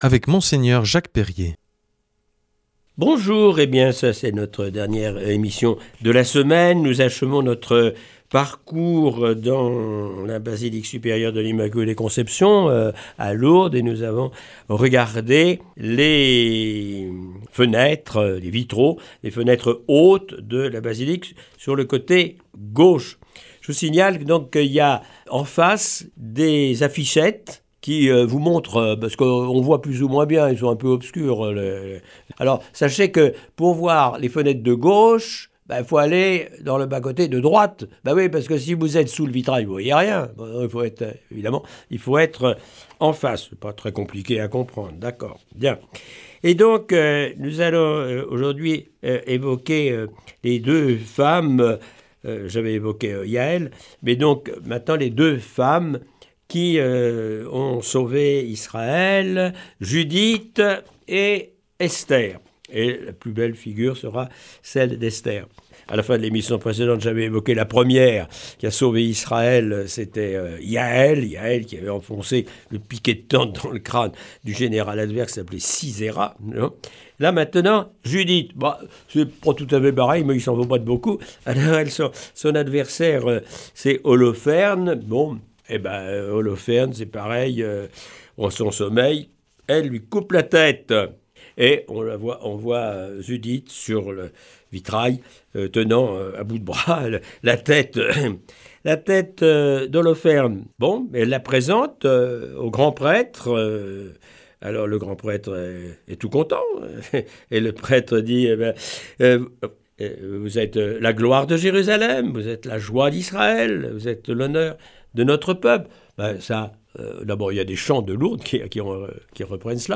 Avec Mgr Jacques Perrier pour guide, nous allons découvrir cette semaine les vitraux ornant la partie haute de la basilique de l’Immaculée Conception au Sanctuaire de Lourdes.